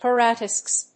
音節par・a・tax・is 発音記号・読み方
/p`ærətˈæksɪs(米国英語), paɹəˈtaksɪs(英国英語)/